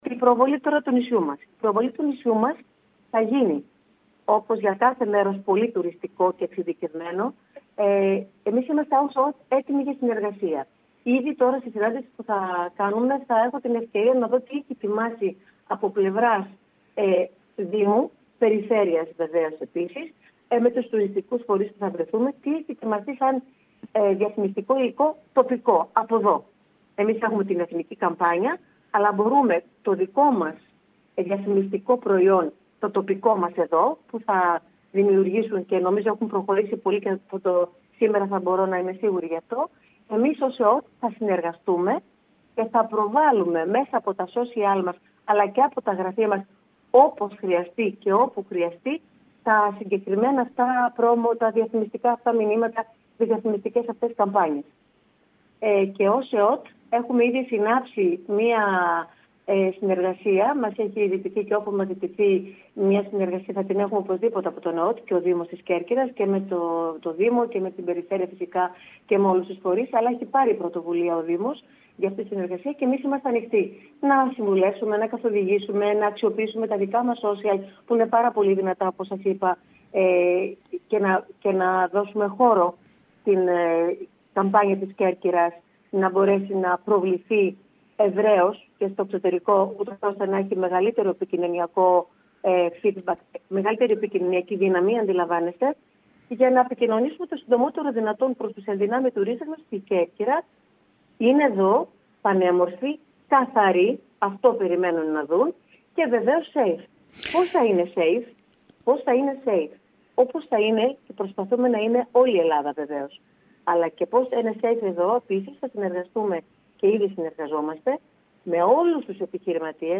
Η Πρόεδρος του ΕΟΤ δήλωσε μιλώντας σήμερα στην ΕΡΑ Κέρκυρας ότι παράλληλα με τη διαφήμιση ολόκληρης της χώρας η οποία θα βγει στον αέρα τις επόμενες μέρες θα υπάρξει και στοχευμένη προβολή επί μέρους τουριστικών προορισμών με βάση τις ιδιαιτερότητες του κάθε τόπου. Το διαφημιστικό υλικό του Δήμου μπορεί να προβληθεί μέσα από το σύστημα των social media που διαθέτει ο ΕΟΤ και το οποίο είναι ισχυρό.